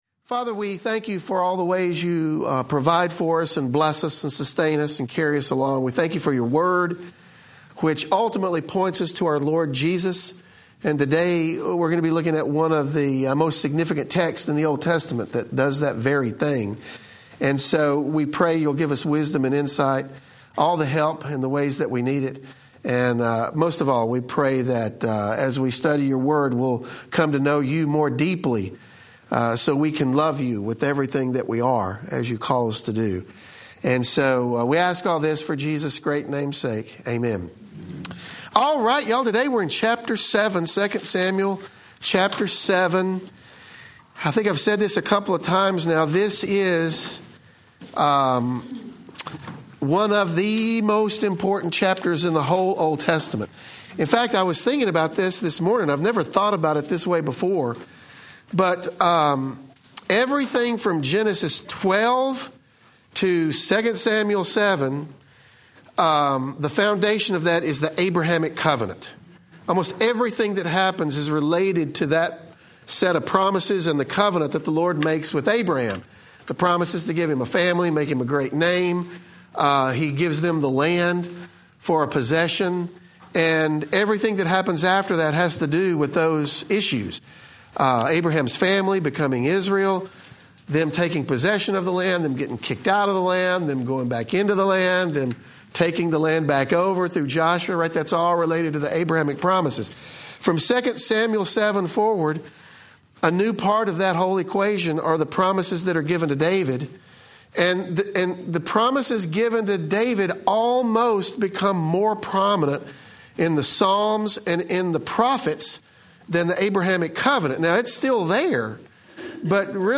The podcast of Truth Seekers Fellowship, FOLLOW presents teaching and discussions centered in a Bible based, Christ centered worldview. Our goal is to help followers of Jesus become mature - effective and fruitful in all of life.